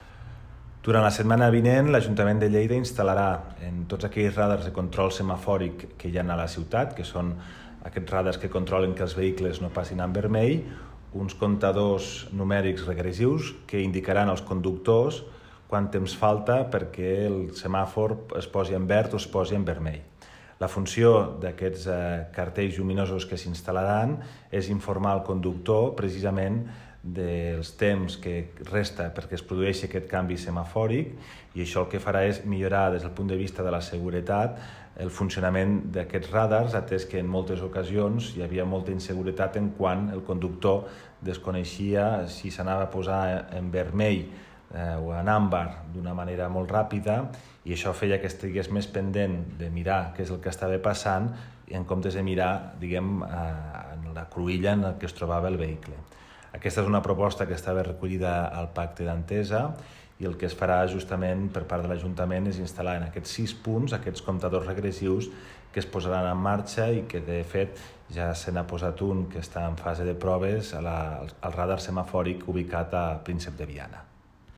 Tall de veu de Toni Postius Ja s’ha instal·lat un d’aquests nous dispositius, en fase de proves, al semàfor foto-vermell que hi ha a l’encreuament entre Príncep de Viana i Prat de la Riba.